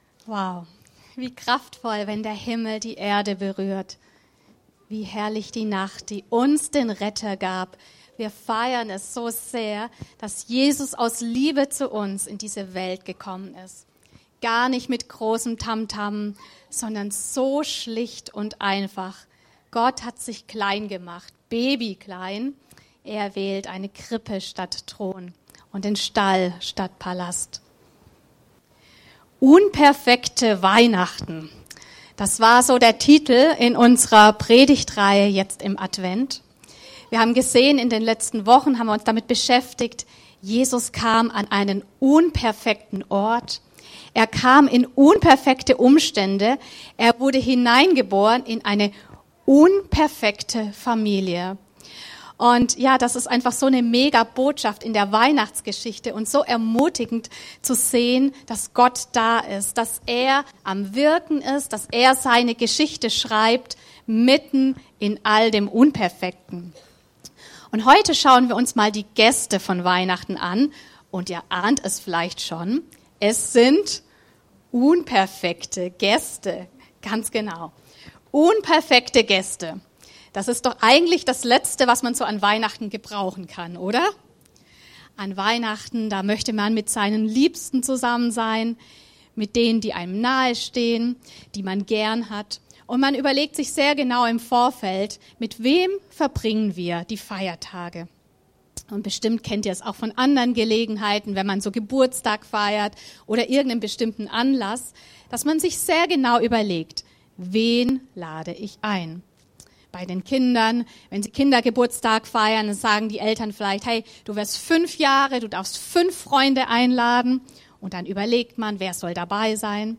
Eine Predigt aus der Reihe (un)perfekte Weihnachten